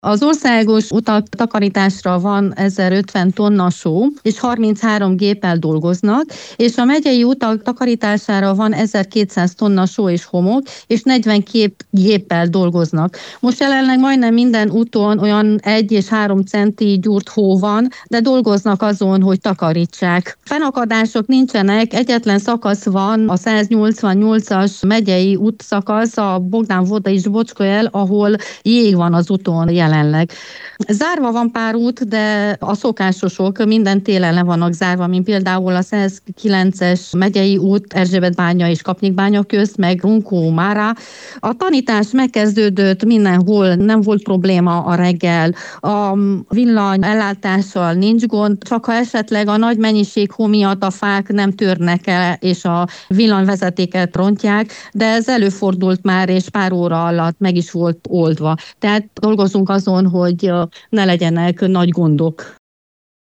Máramaros megyében is több tonna csúszásmentesítő anyag áll rendelkezésre, a megye alprefektusa, Krizsanovszki Enikő szerint nagyobb fennakadásokat nem tapasztaltak.